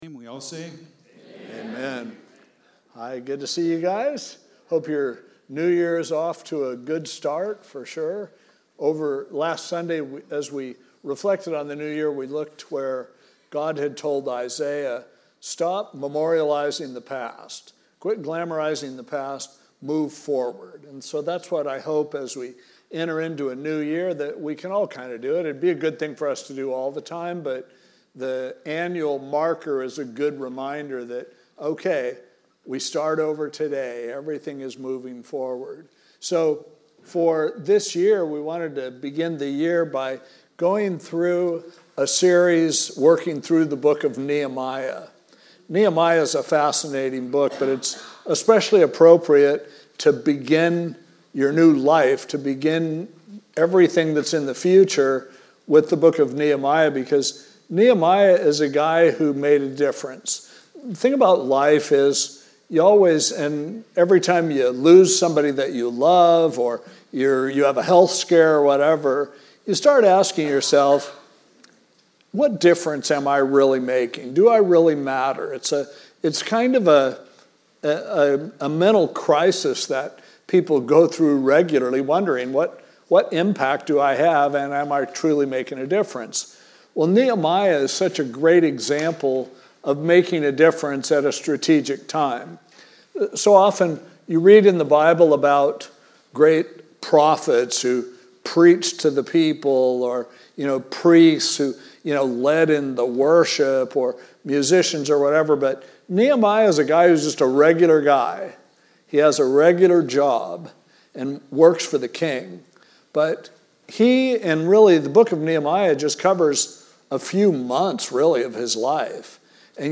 This podcast features weekly audio messages, special speakers, and special event audio at Pacific Hills Calvary Chapel.